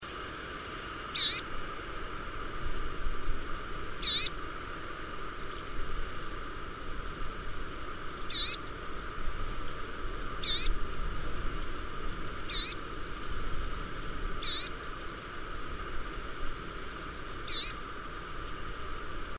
Pensaspunavarpunen / Red-mantled Rosefinch (Carpodacus rhodochlamys)
Female calling from a tree (190 KB).